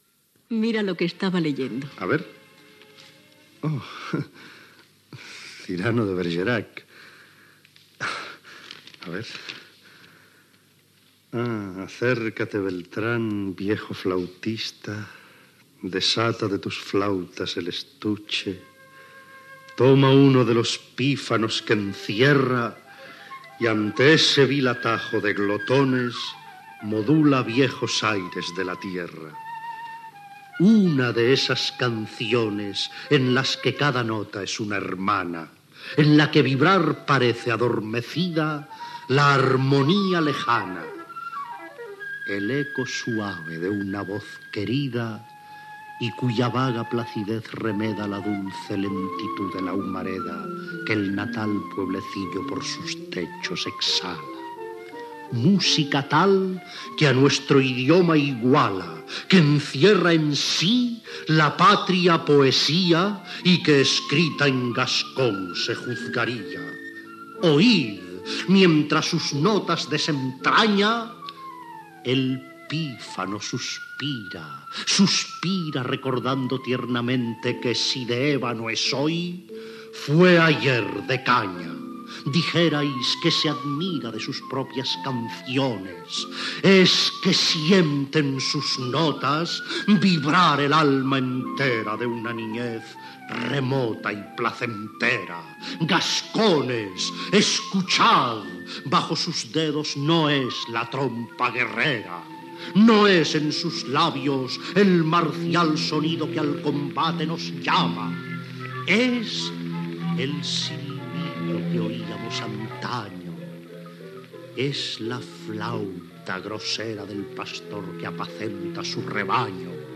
Adaptació radiofònica de l'obra "Mariscal", de Molnar.
Fragment de diàleg entre els protagonsites: un comediant enamorat de la comtessa i receitat d'un fragment de l'obra "Cyrano de Bergerac"